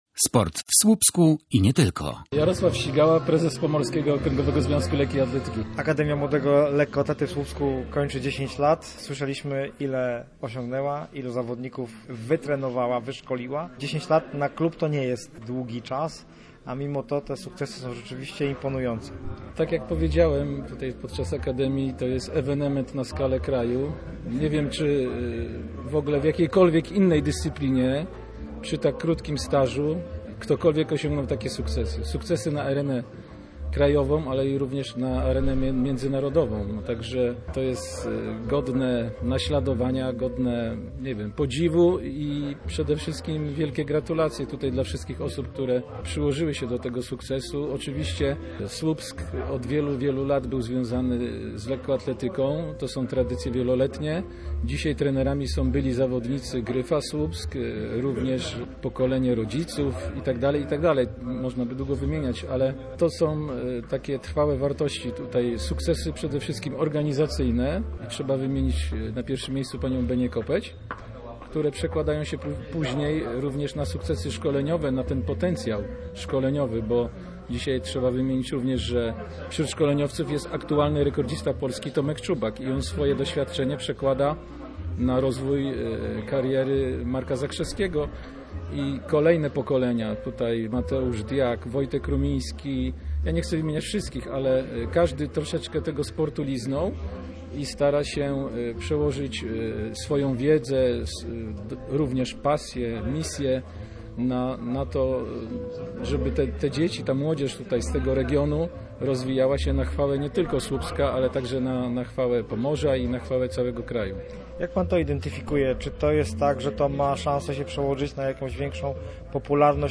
Sto trzydzieści jeden medali Mistrzostw Polski to dorobek Akademii Młodego Lekkoatlety w Słupsku. Klub podsumował dziesięcioletnią działalność na gali w auli Uniwersytetu Pomorskiego w Słupsku.
Posłuchaj materiału reportera Radia Gdańsk: https